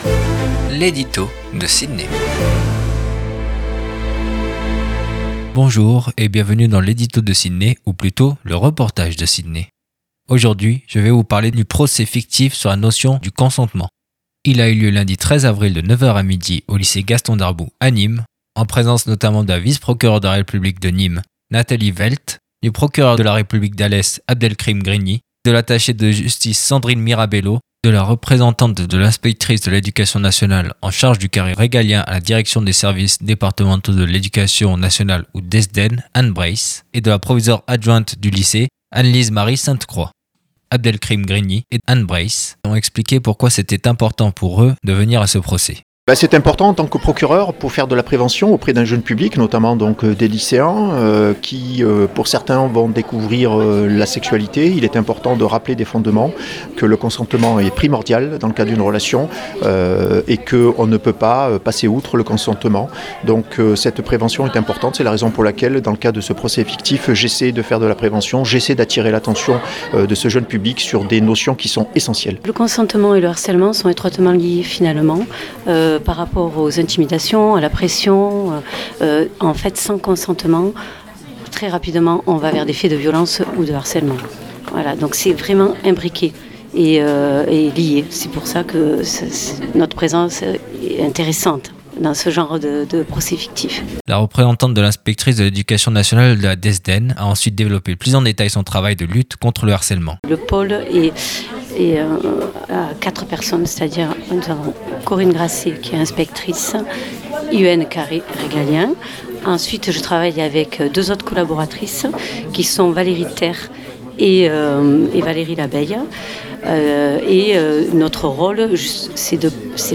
REPORTAGE SUR LE PROCES FICTIF SUR LA NOTION DU CONSENTEMENT